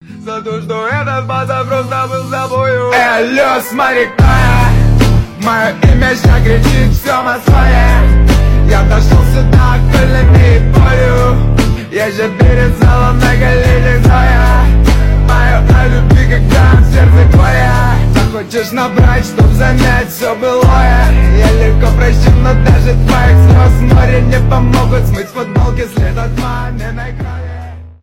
рэп , басы